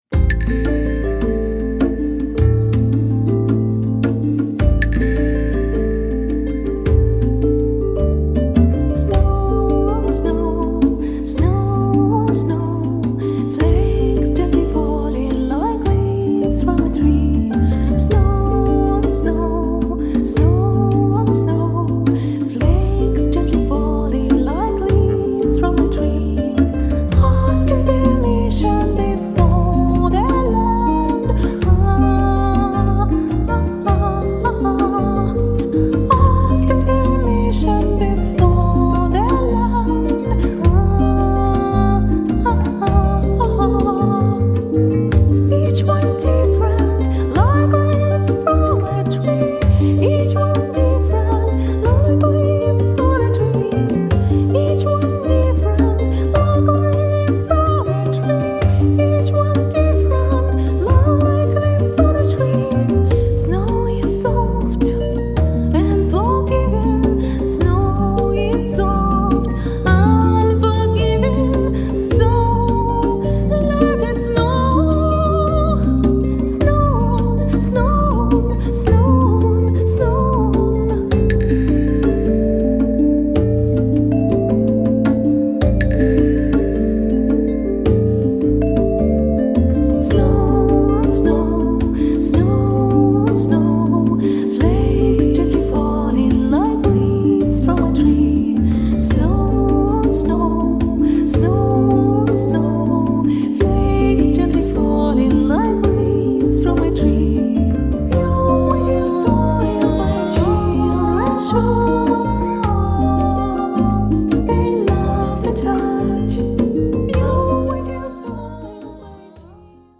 voice
keyboards
flute
clarinet
bassoon
violin
harp